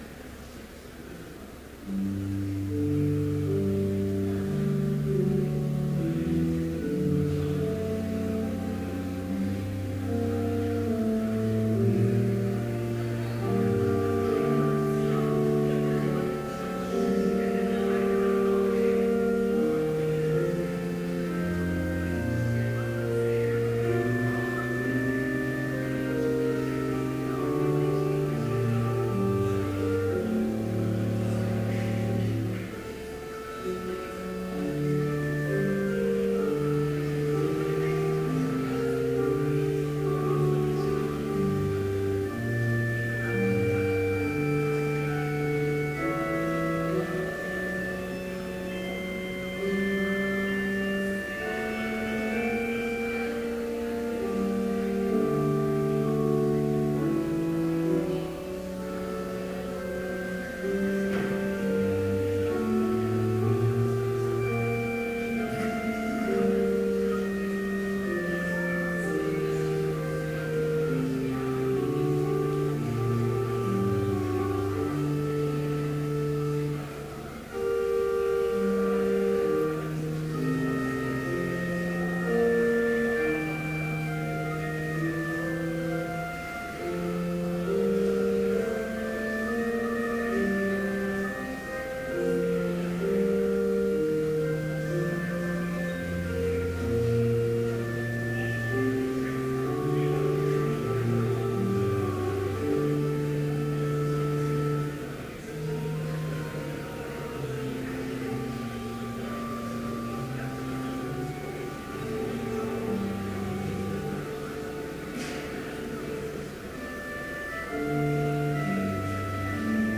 Complete service audio for Chapel - March 20, 2014